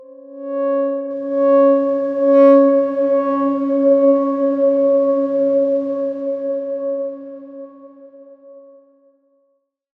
X_Darkswarm-C#4-mf.wav